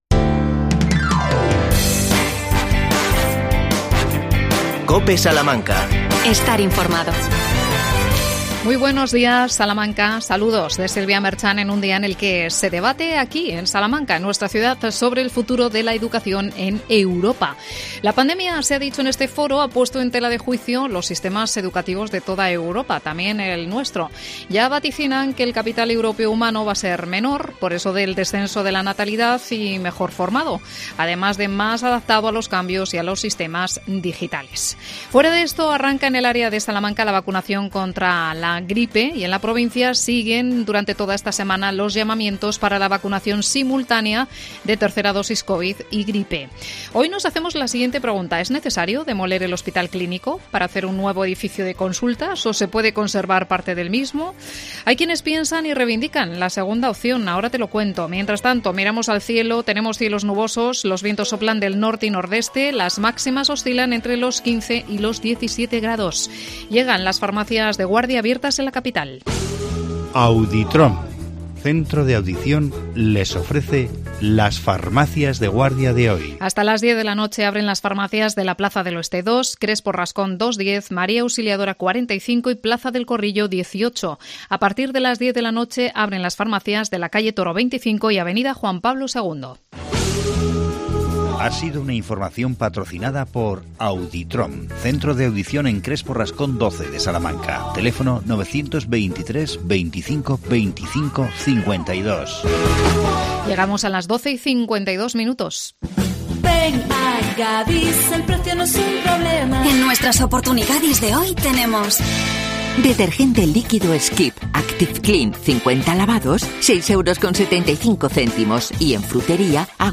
Demolición del Clínico ¿Sí o no?Entrevistamos